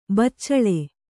♪ baccaḷe